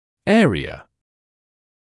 [‘eərɪə][‘эариэ]область; участок; пространство